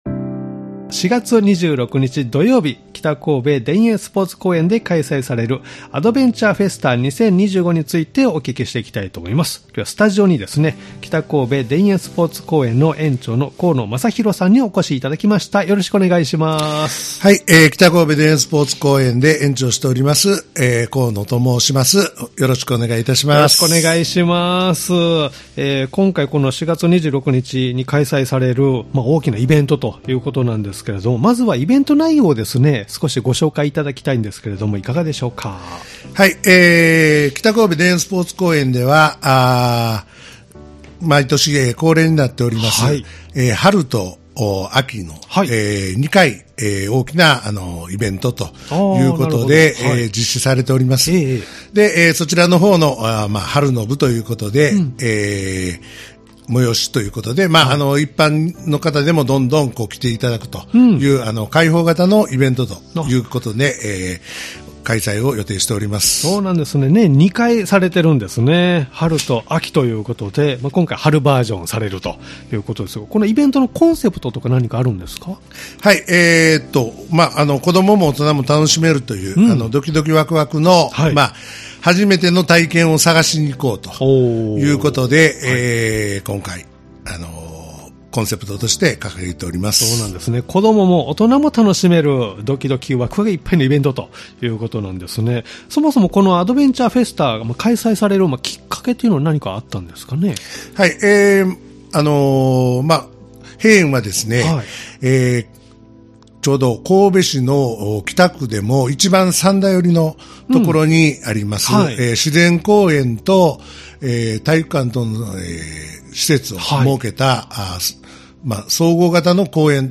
様々なジャンルで活動・活躍されている方をお迎えしてお話をお聞きするポッドキャスト番組「カフェテラス」（再生ボタン▶を押すと放送が始まります）